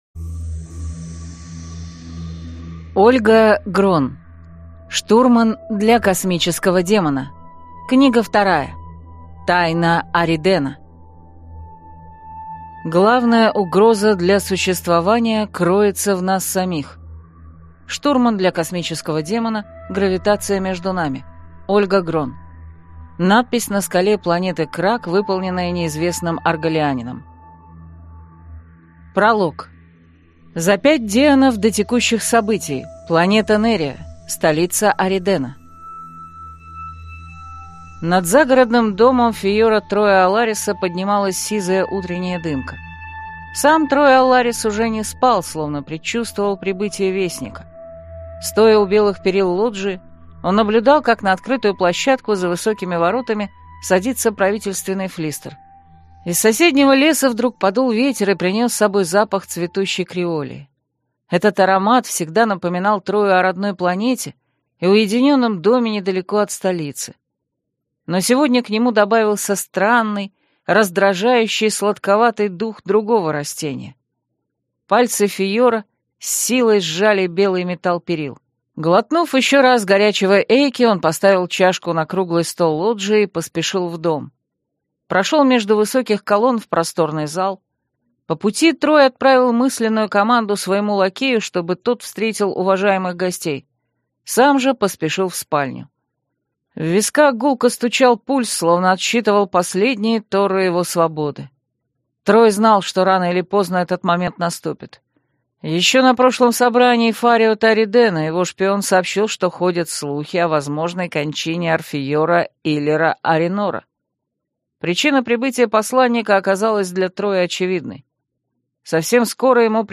Аудиокнига Штурман для космического демона. Тайна Аридена | Библиотека аудиокниг